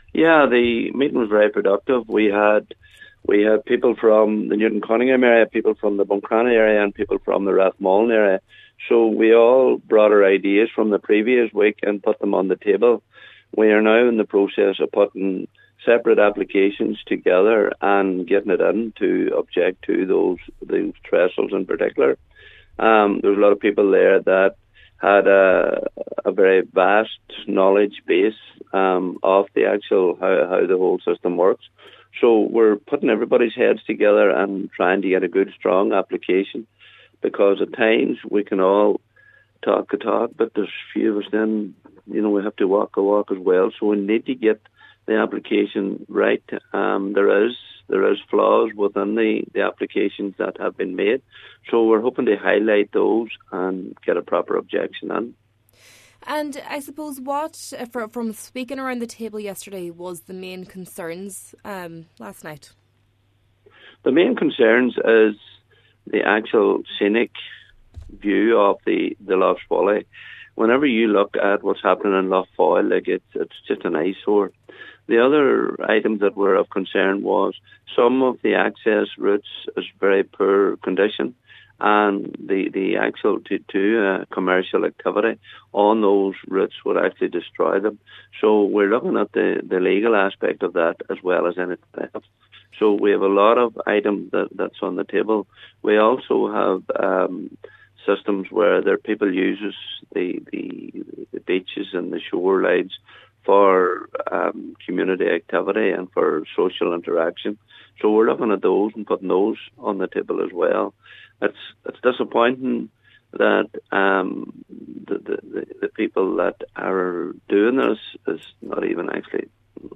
Cathaoirleach of Donegal County Council, Cllr Paul Canning, says there are numerous reasons why people are objecting, including potential damage to the tourism industry, biodiversity concerns and risks around public access.